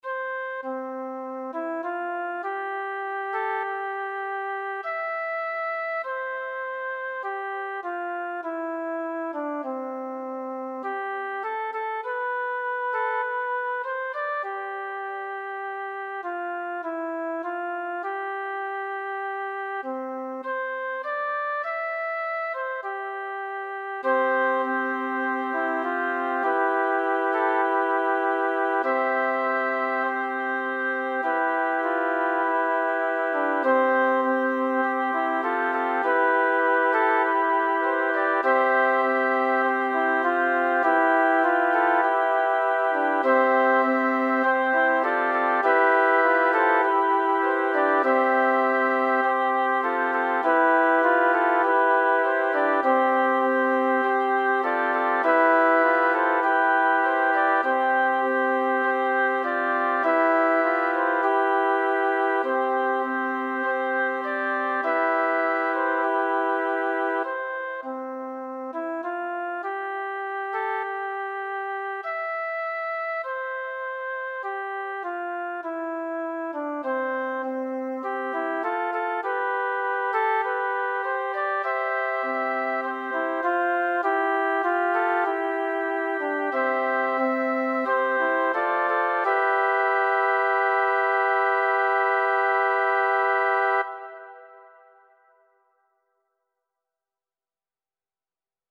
“Melodía para Geografía con letra de J. W. Goethe„
Voz